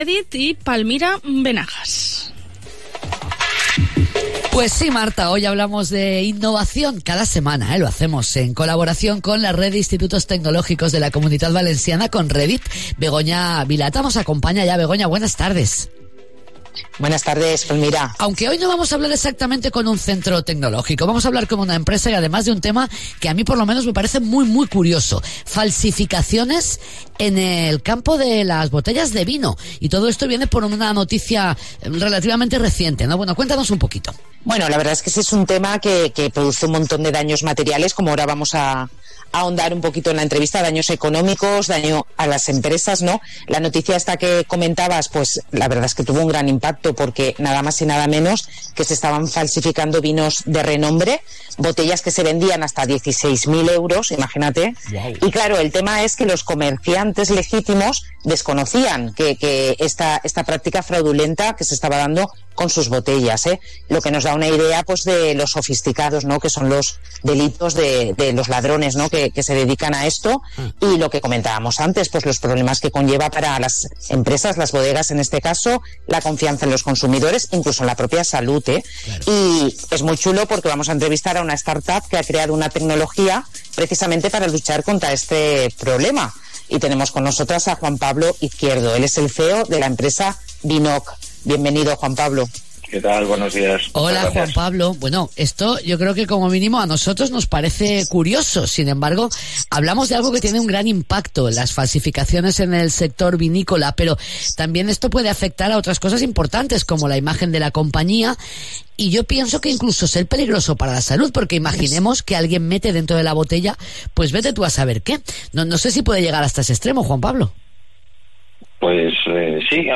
Entrevista Vinok sobre falsificación del vino